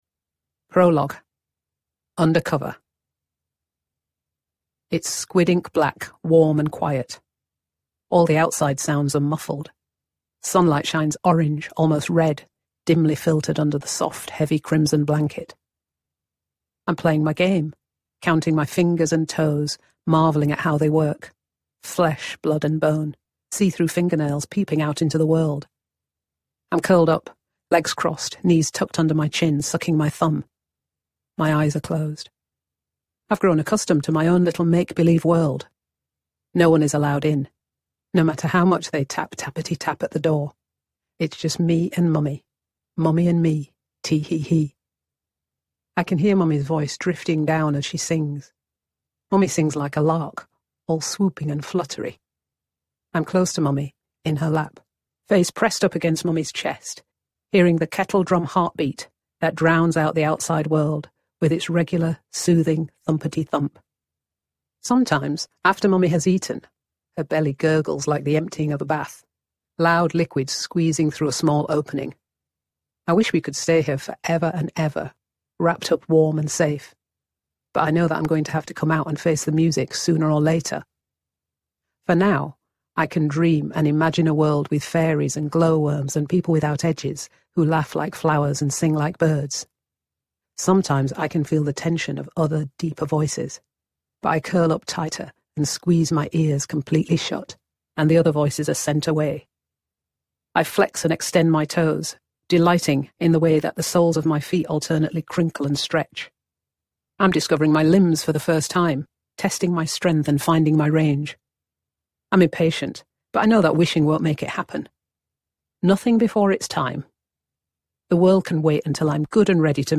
Audiobook sample
The-Mercy-Step-Audiobook-Excerpt.mp3